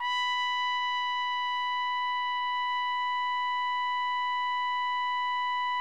TRUMPET   30.wav